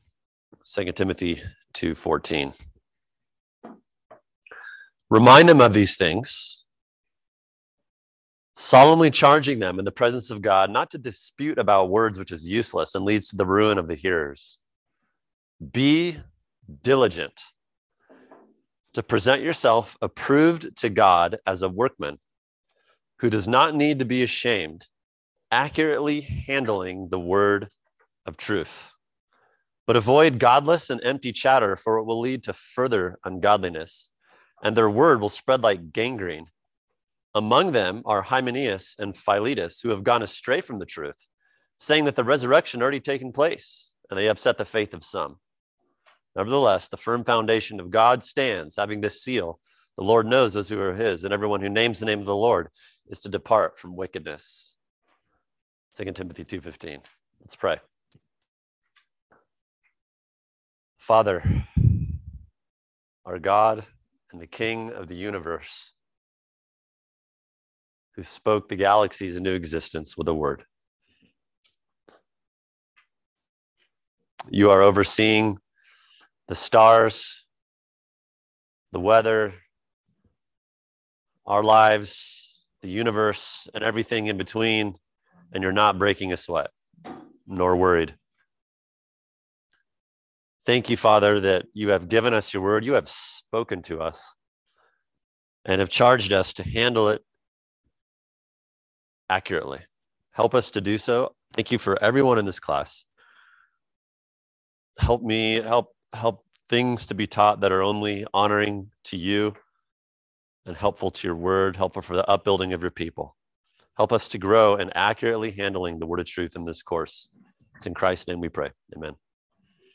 [equipping class] Hermeneutics Lesson 1 – Introduction | Cornerstone Church - Jackson Hole